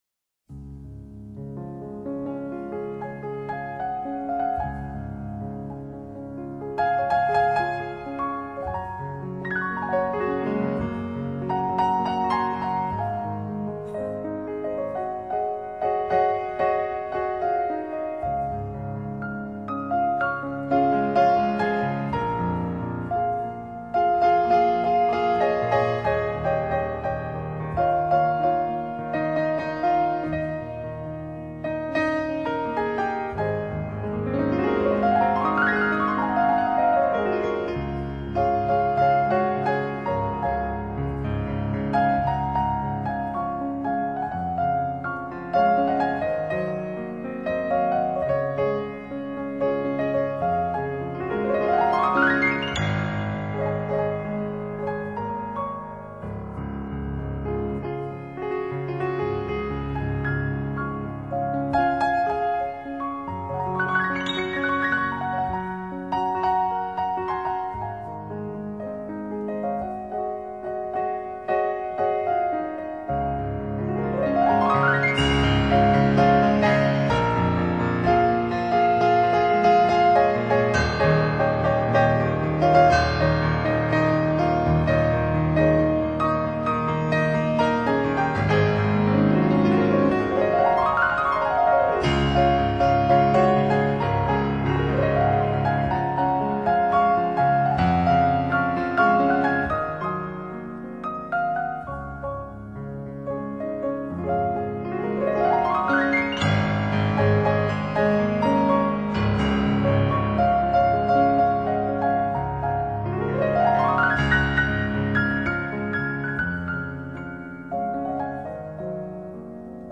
相当hi—fi，钢琴声出 得清脆，伴奏弦乐丰厚可人，达发烧级程度的整体表现。